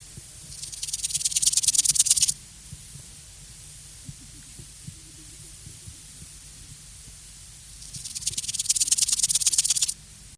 Акустические сигналы: одиночный самец, Россия, Тува, Эрзинский район, западная часть нагорья Сенгелен, запись
Температура записи 20-22° С.